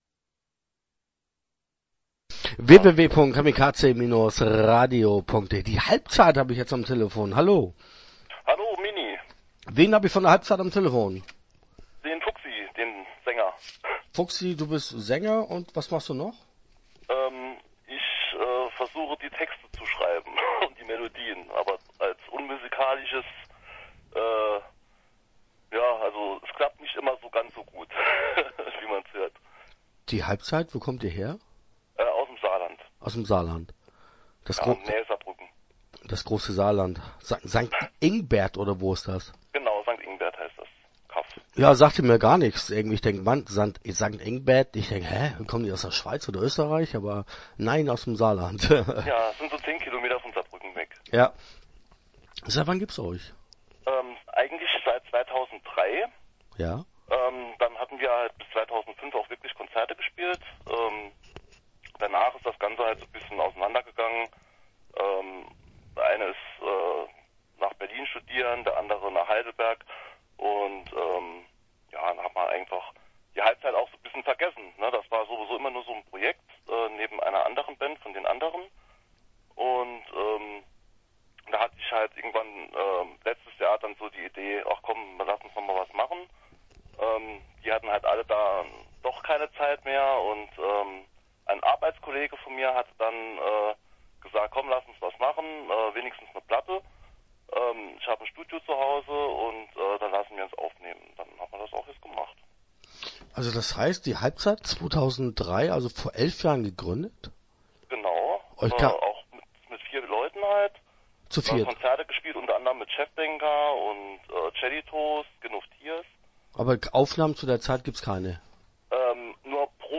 Start » Interviews » Die Halbzeit